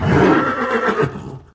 ogg / general / combat / creatures / horse / he / attack3.ogg